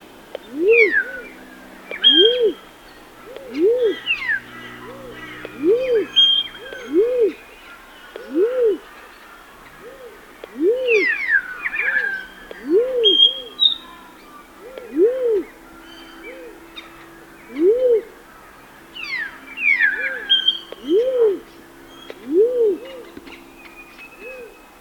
Crested Pigeon
wugh-wugh-wugh (Bundjalung)
Ocyphaps lophotes
Songs & Calls
Loud coo-whoo or coo.
Crested-Pigeon.mp3